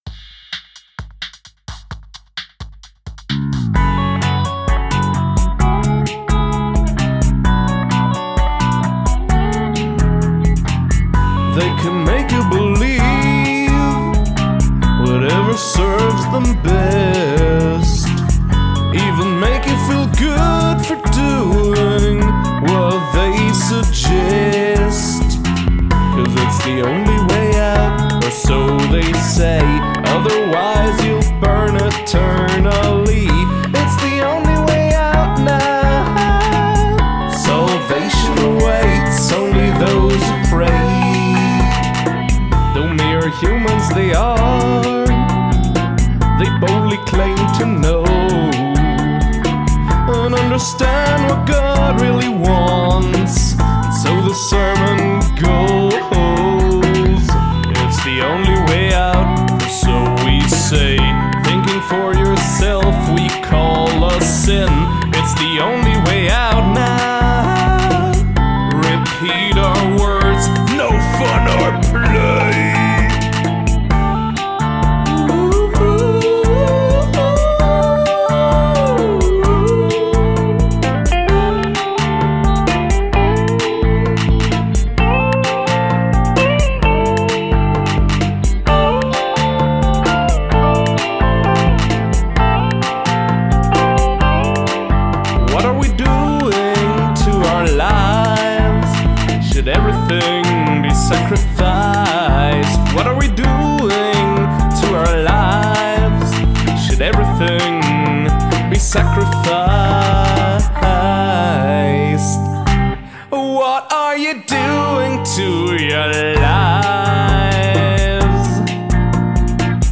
Downward Modulation